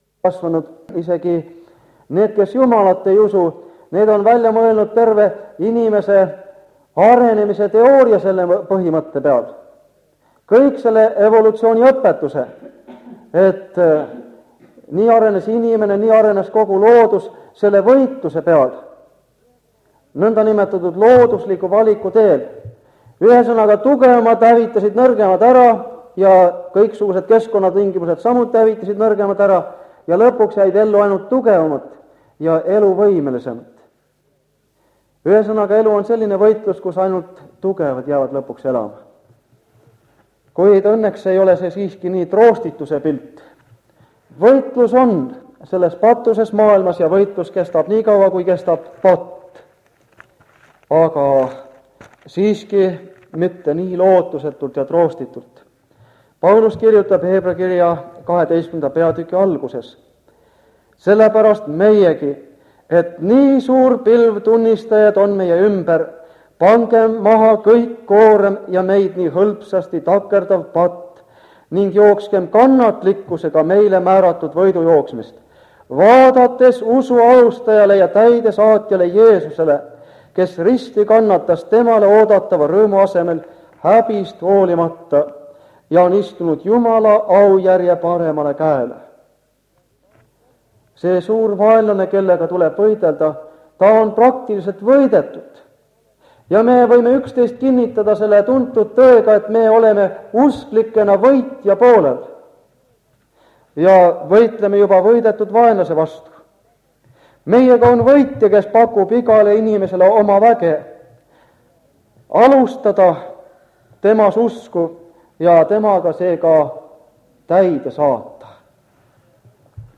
Ilmutuse raamatu seeriakoosolekud Kingissepa linna adventkoguduses